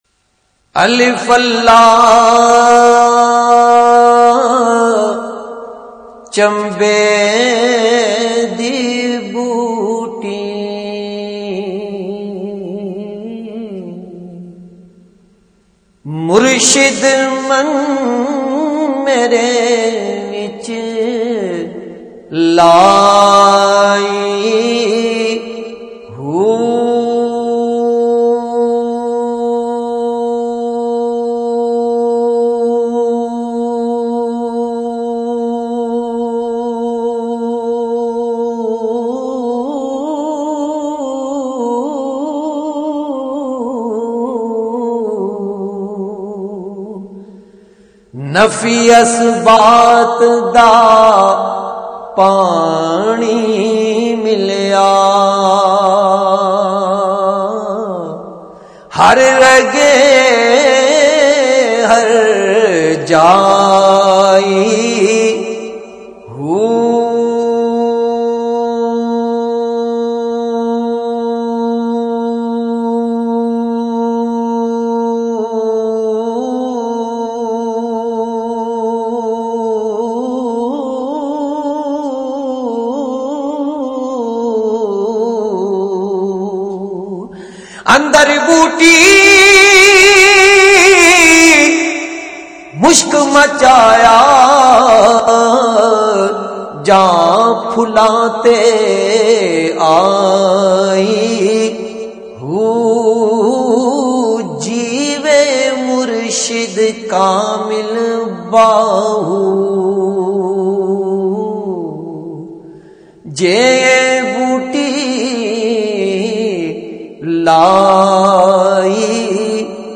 Naat
in best audio quality